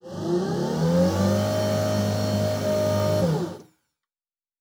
pgs/Assets/Audio/Sci-Fi Sounds/Mechanical/Servo Big 4_1.wav at master
Servo Big 4_1.wav